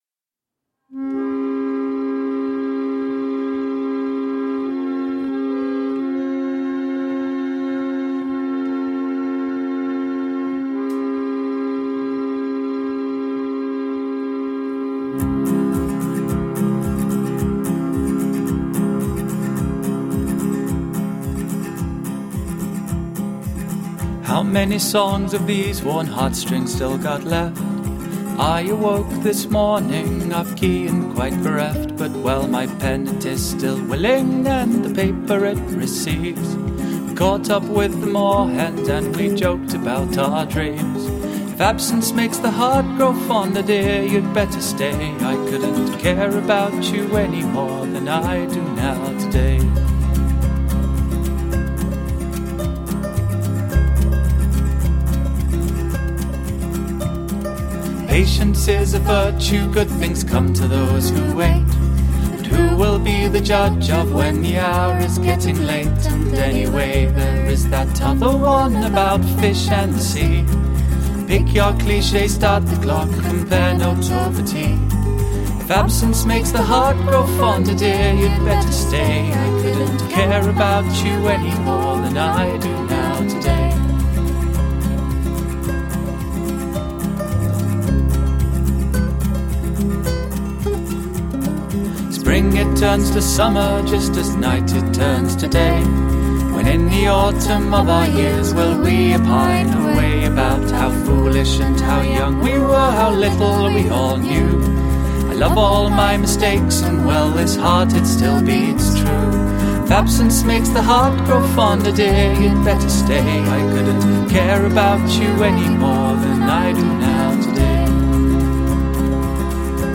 Gently experimental nu-folk.